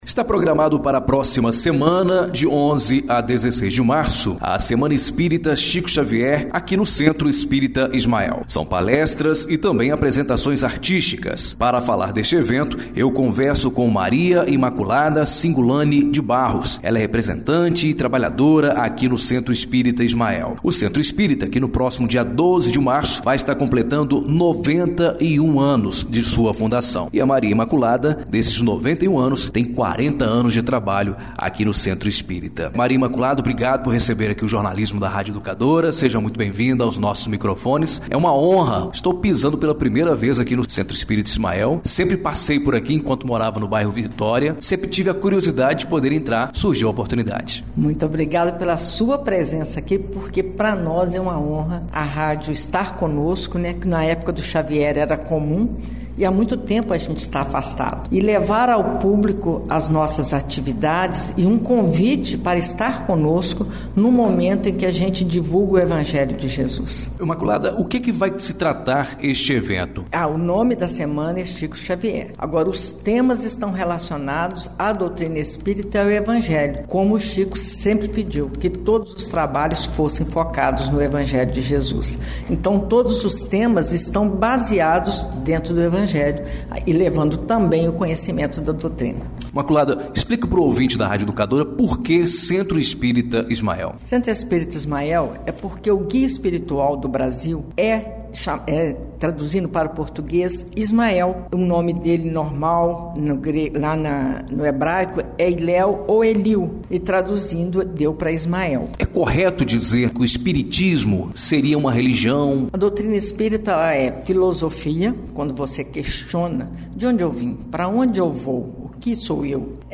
Entrevista exibida na Rádio Educadora Ubá – MG